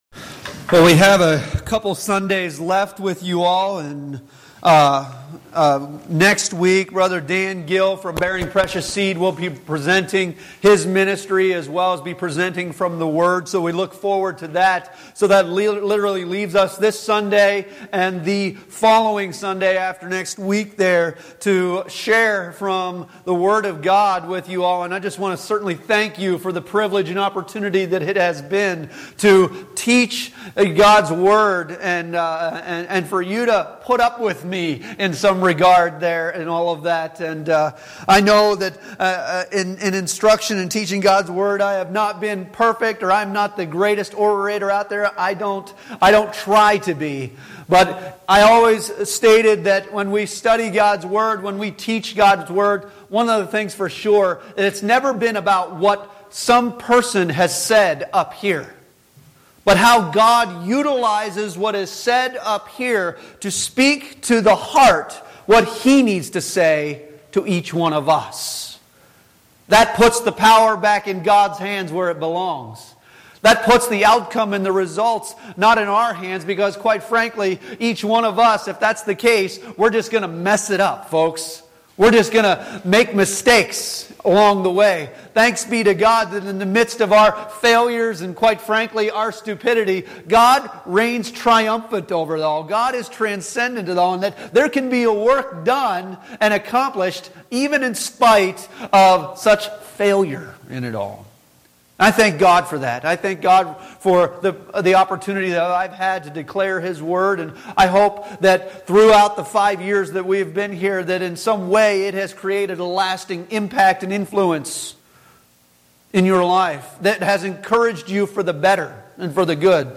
4-6 Service Type: Sunday Morning Worship Bible Text